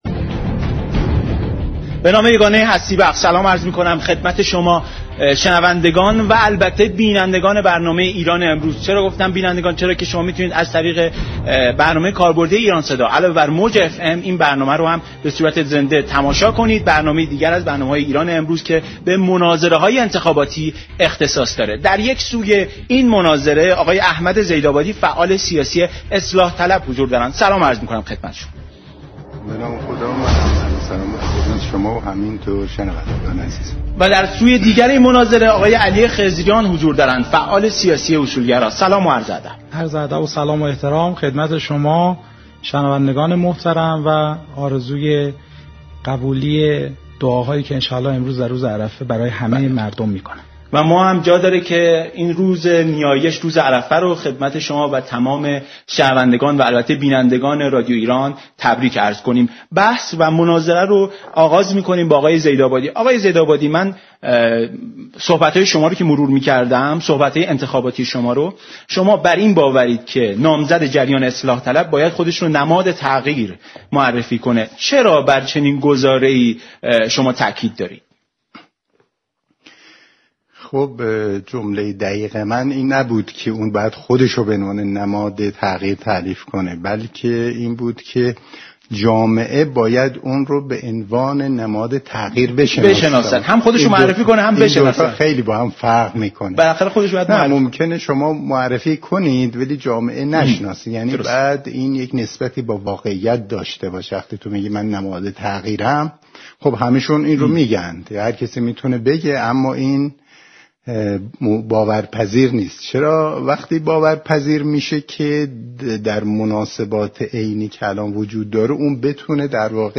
امروز یكشنبه 27 خرداد، در سومین میز مناظره برنامه ایران‌امروز رادیو ایران میزبان، احمدزیدآبادی فعال اصلاح‌طلب و و علی خضریان فعال اصولگرا بود.